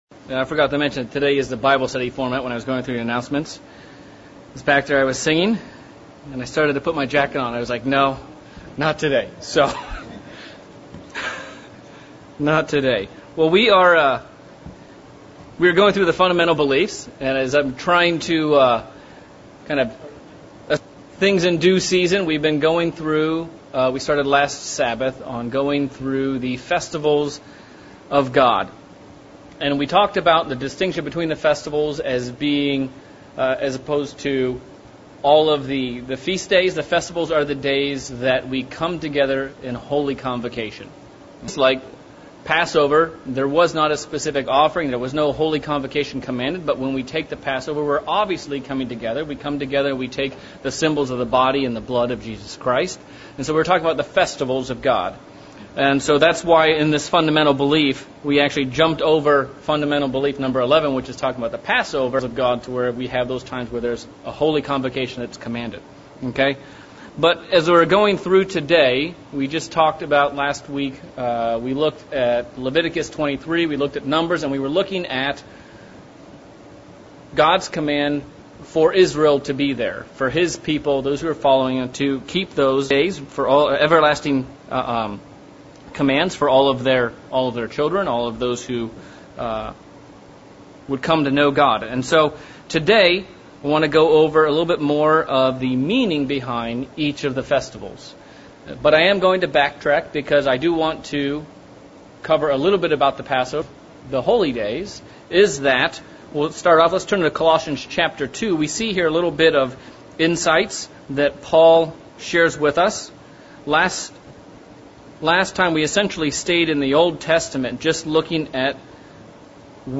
Sermon looking at the subject of our Christian walk and how we can work to be sure we are at Jesus's side when he returns